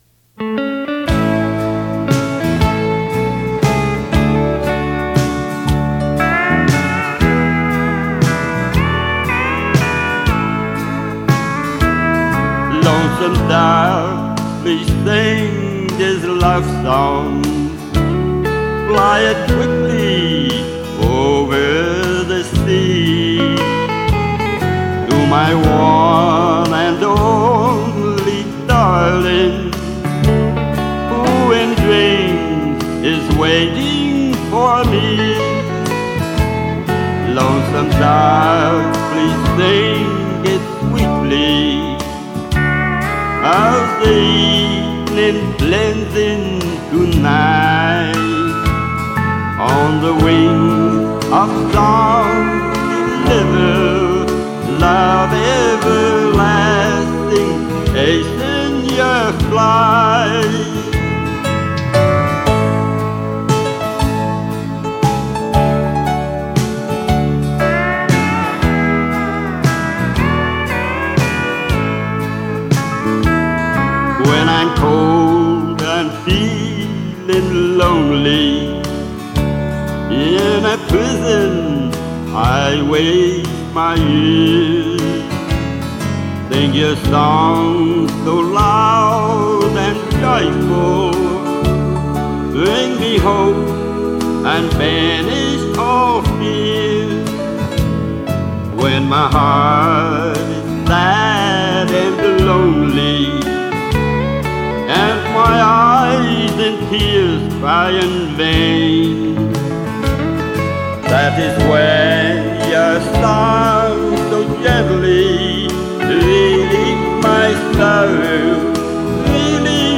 He must be 162 years old!